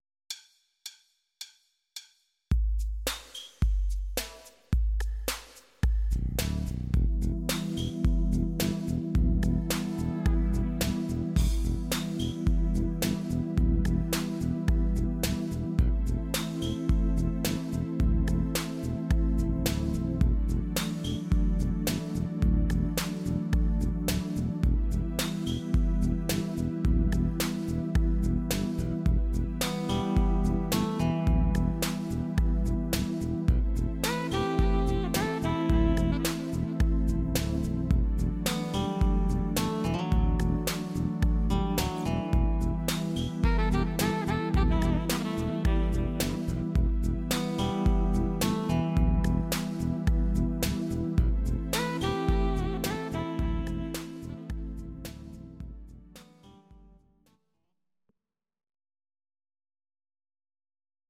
Audio Recordings based on Midi-files
Musical/Film/TV, Jazz/Big Band, Instrumental, 1980s